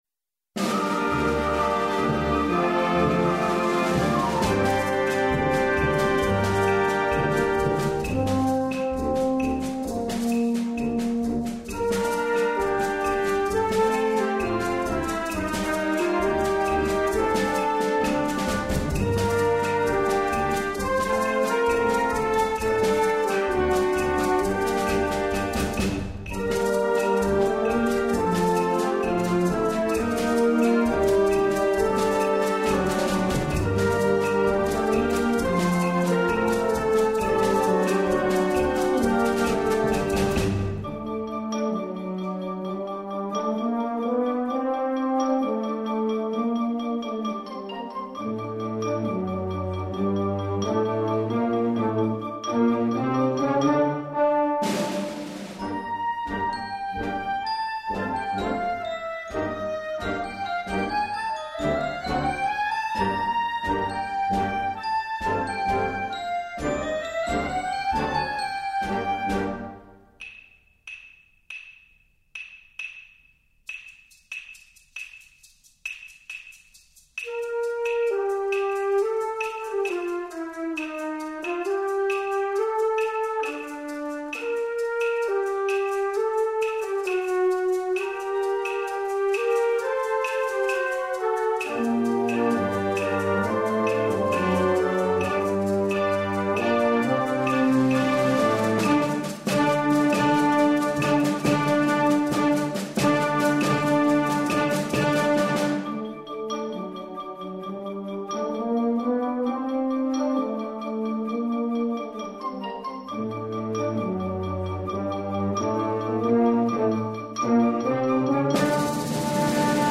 Gattung: Konzertante Blasmusik
2:25 Minuten Besetzung: Blasorchester PDF